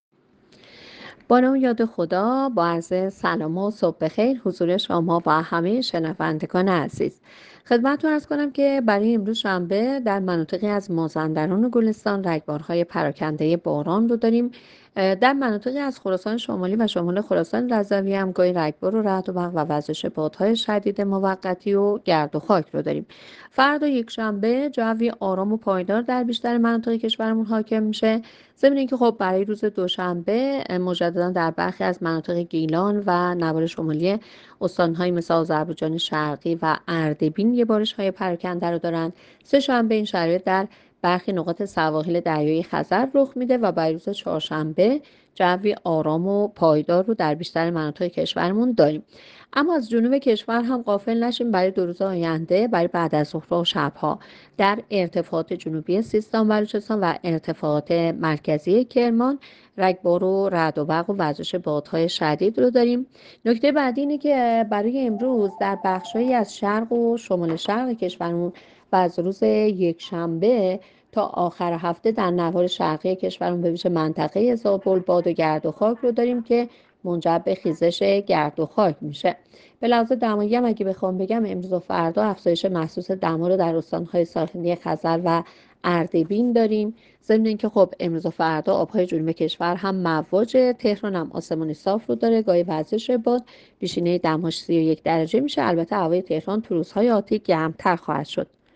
گزارش رادیو اینترنتی پایگاه‌ خبری از آخرین وضعیت آب‌وهوای ۲۷ اردیبهشت؛